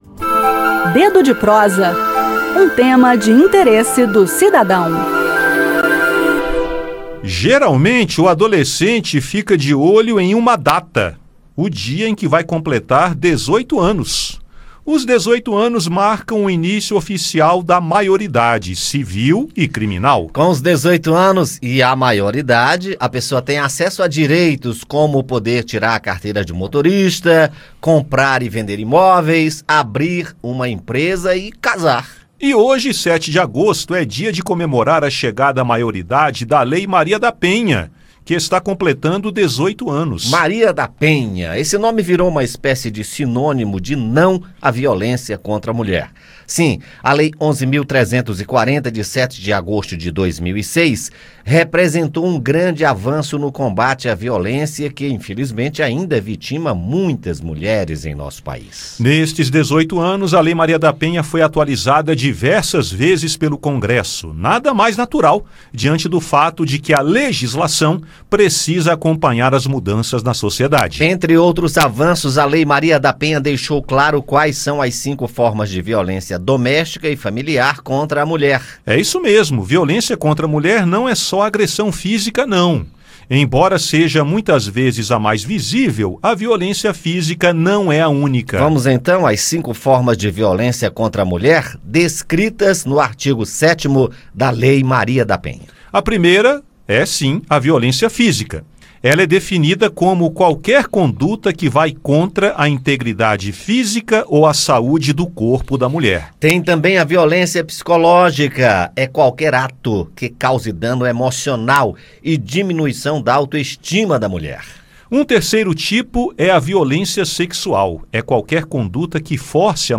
7 de agosto é dia de comemorar os 18 anos da Lei Maria da Penha (Lei 11.340), a qual representou um grande avanço no combate à violência contra mulheres por incluir o crime de violência doméstica no Código Penal. Ouça mais no bate-papo, e entenda os avanços da Lei Maria da Penha nesses 18 anos, entre eles a tipificação das cinco formas de violência doméstica e familiar contra a mulher: violência física, psicológica, sexual, patrimonial e moral.